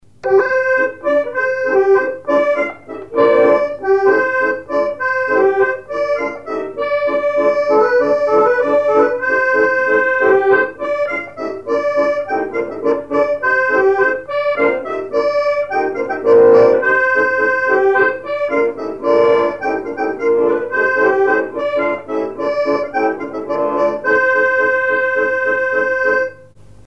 danse : ronde : grand'danse
instrumentaux à l'accordéon diatonique